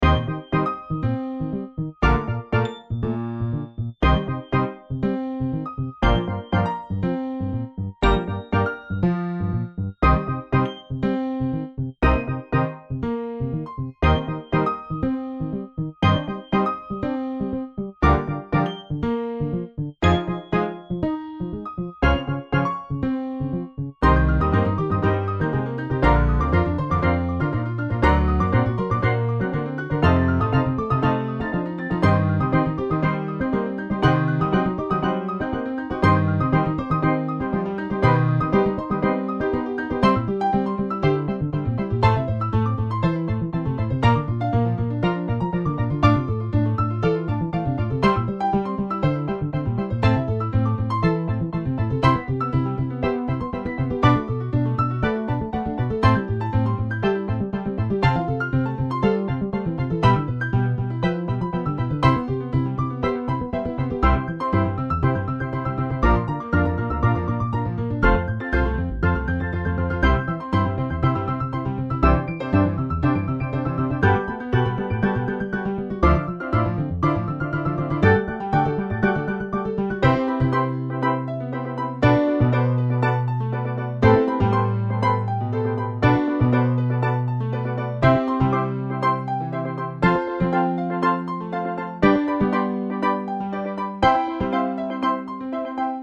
ピアノ、アコースティックベース、スティールドラム